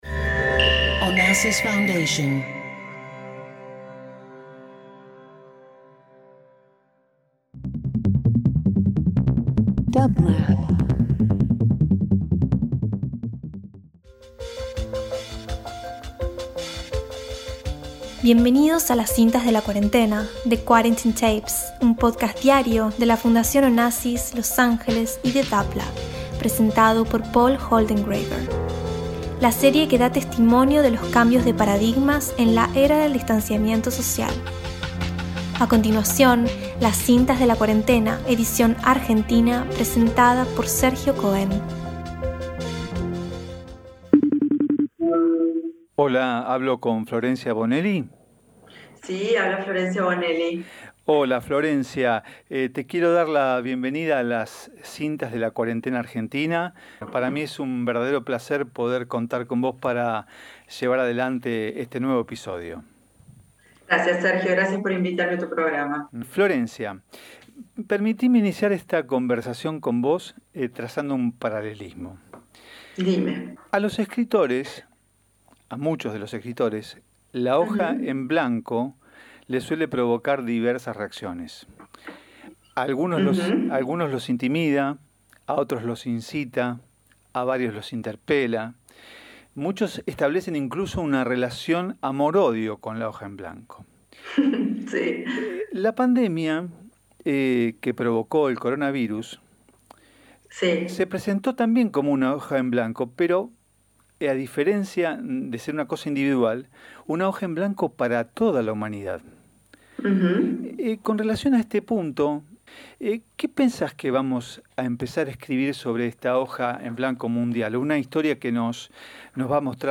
Interview Talk Show